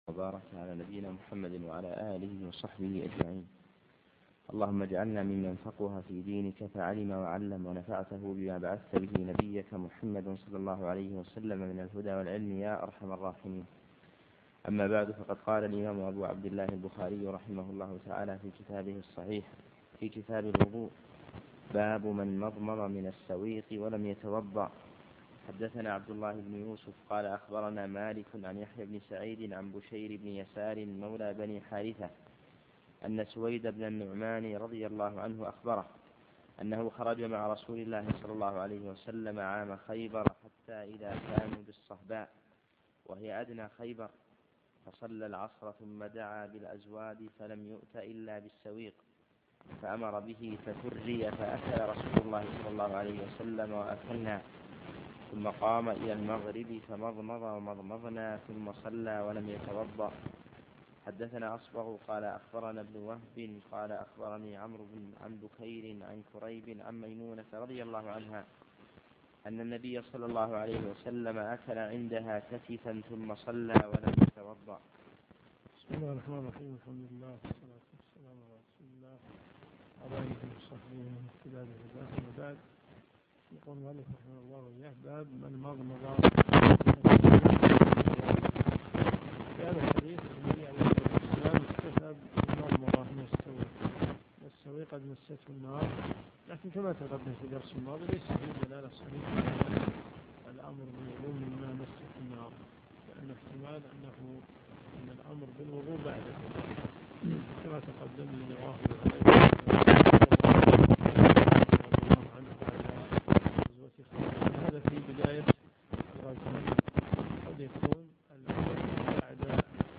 كتاب الوضوء - من حديث 209 -إلى- حديث 218 - الصوت متقطع إلى الدقيقة 5.